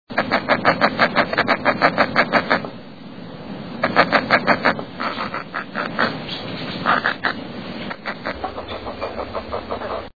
Roseate Spoonbill
Roseate_Spoonbill.mp3